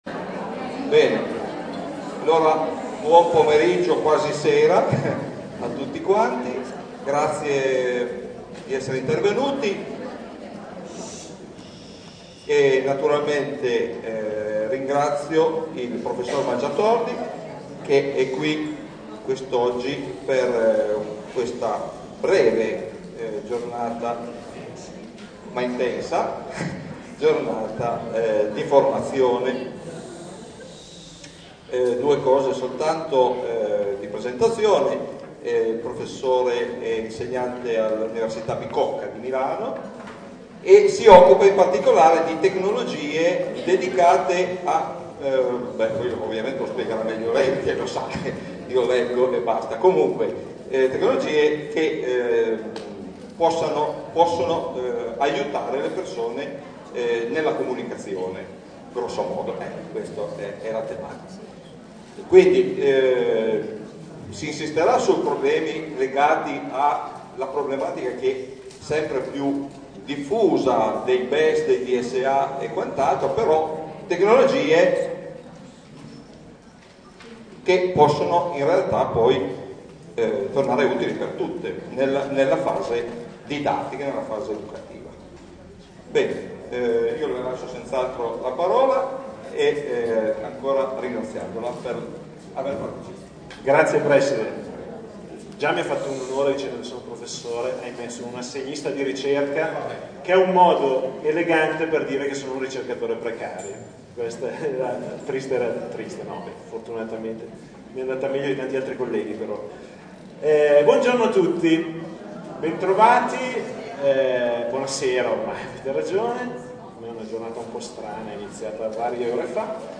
REGISTRAZIONE DELL’INCONTRO